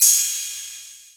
Hats & Cymbals
Cymbal_01.wav